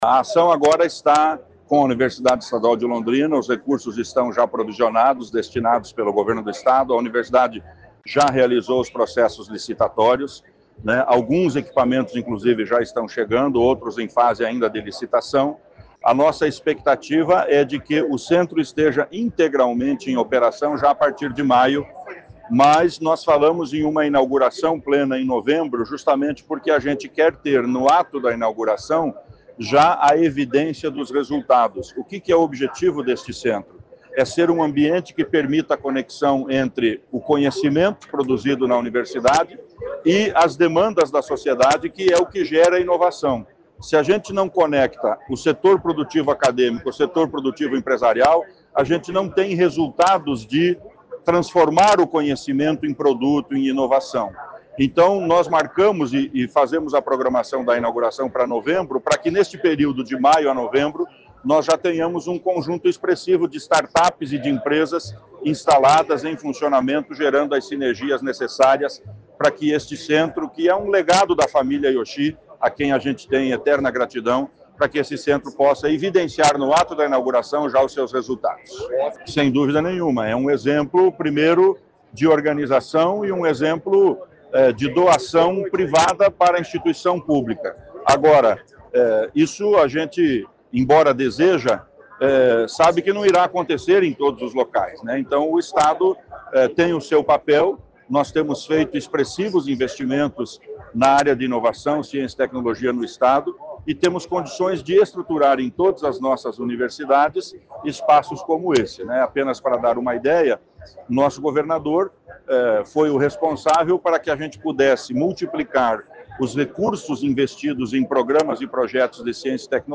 Sonora do secretário Estadual da Ciência, Tecnologia e Ensino Superior, Aldo Bona, sobre a entrega do prédio do Centro de Inovação Tecnológica da UEL